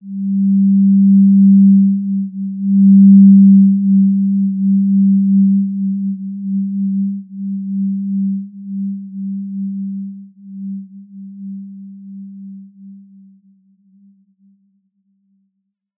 Basic-Tone-G3-mf.wav